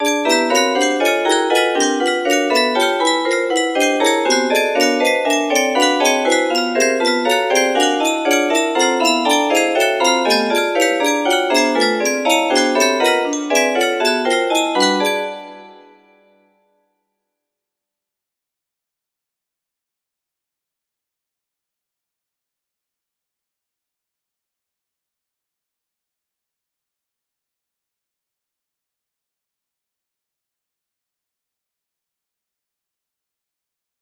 P14 music box melody